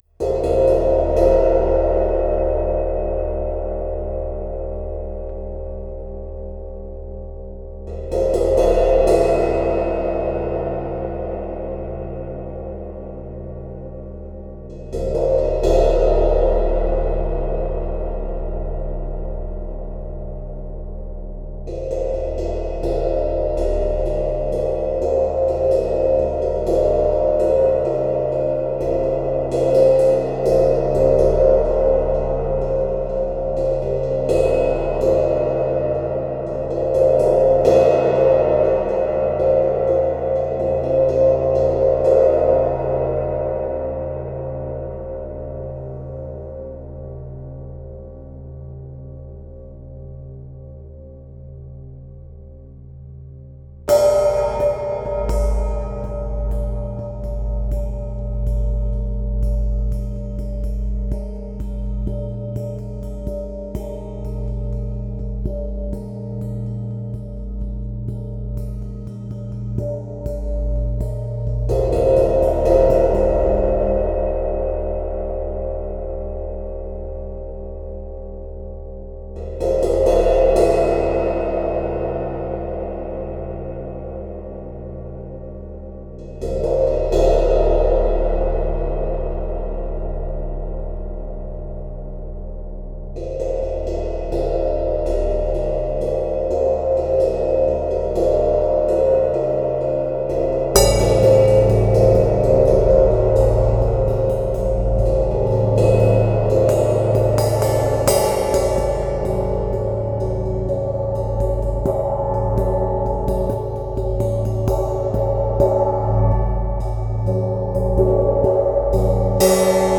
aber nicht so poprockblablablaweise, sondern als klangkörper, und bitte ohne rauschen und mit allen obertönen, vom bass bis hiss. so schnappte ich alles was hier lag: ein meinl meteor hihat (top und bottom) und ein zultan aja crash. also so ziemlich unterste schublade becken - aber mit charakter.
zwei at2020 ca. 10cm/90° von den becken entfernt in fireface uc mit 30db gain, becken lagen umgedreht an den schaumstoffkissen, gespielt war sehr sachte mit fingerkuppen, mit nägeln und mitm stock, abwechselnd. bin für feedbacks aller art dankbar.